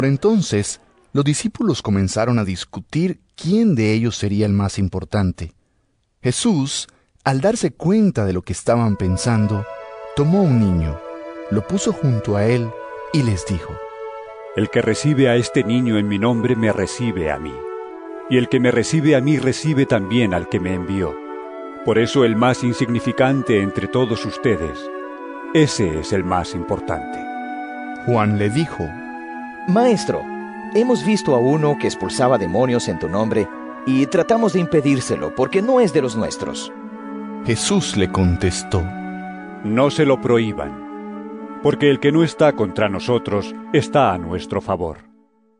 Lc 9 46-56 EVANGELIO EN AUDIO